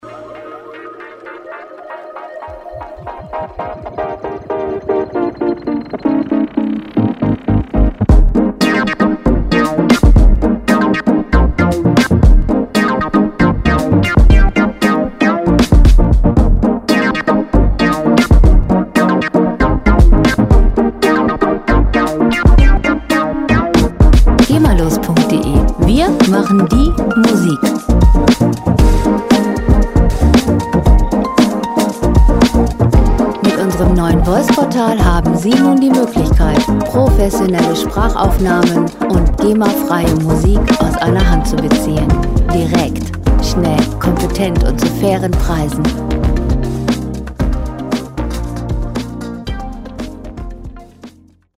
Lounge Musik - Cool und lässig
Musikstil: Nu-Funk
Tempo: 116 bpm
Tonart: F-Moll
Charakter: launig, gutgelaunt